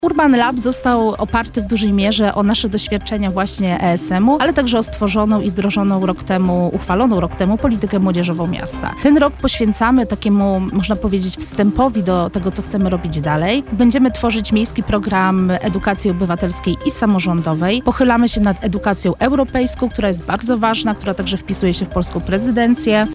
[PORANNA ROZMOWA] Urban Lab Lublin- to tutaj młodzi decydują o przyszłości miasta